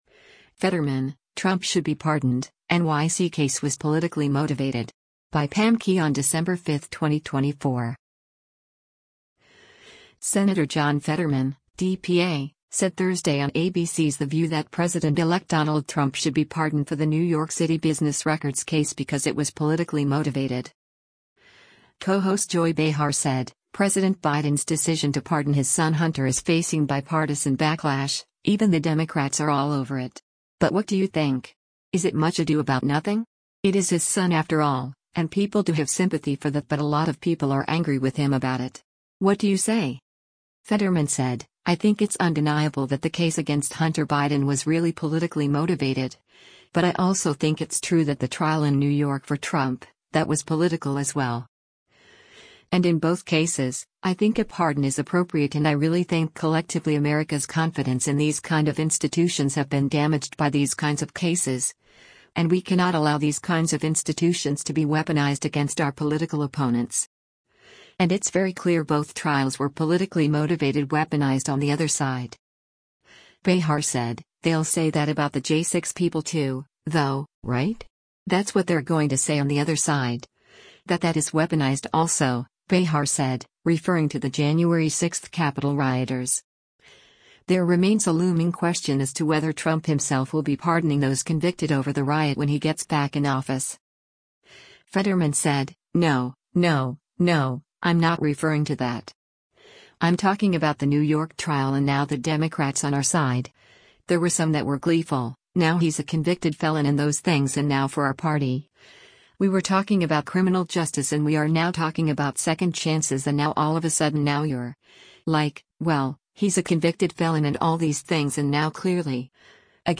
Senator John Fetterman (D-PA) said Thursday on ABC’s “The View” that President-elect Donald Trump should be pardoned for the New York City business records case because it was “politically motivated.”